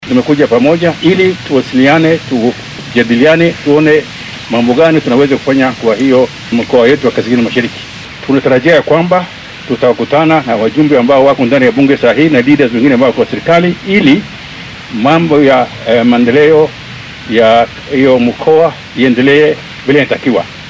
Senatarkii hore ee dowlad deegaanka Mandera Maxamad Macallin Maxamuud(Muumat) oo faahfaahin ka bixinaya shirka ay yeesheen ayaa yidhi.
Senatarkii-hore-ee-Mandera.mp3